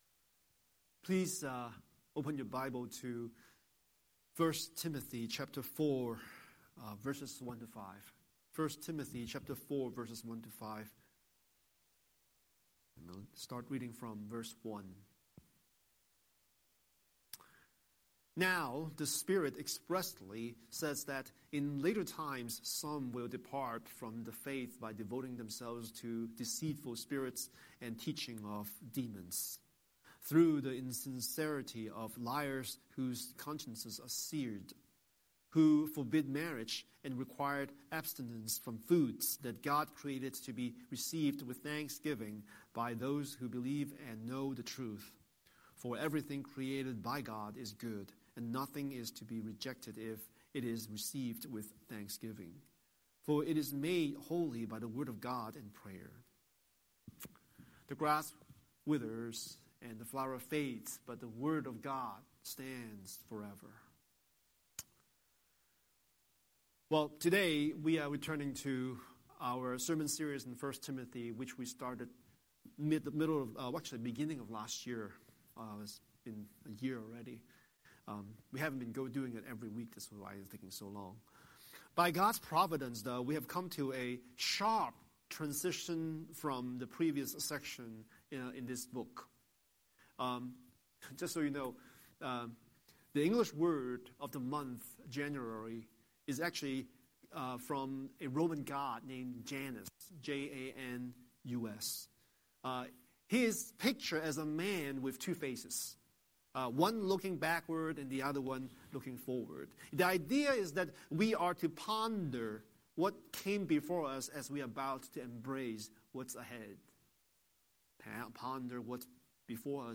Scripture: 1 Timothy 4:1–5 Series: Sunday Sermon